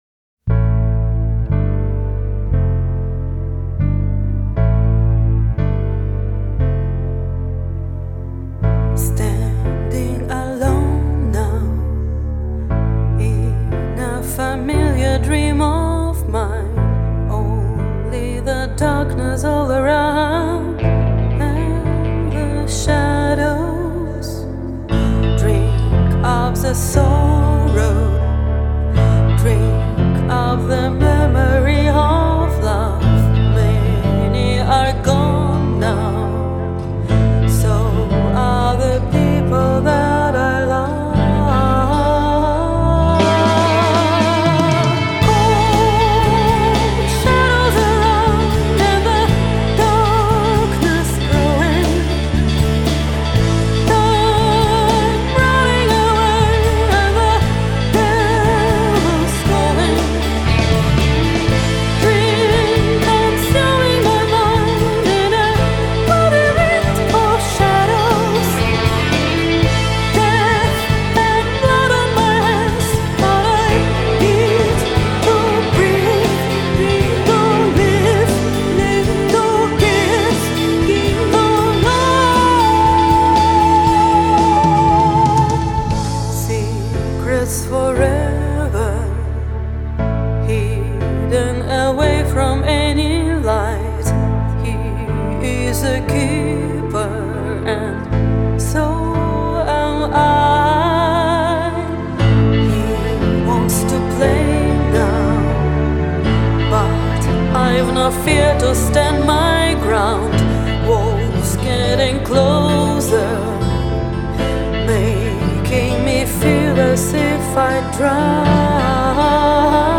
Backup vocals
Bass guitar
Drums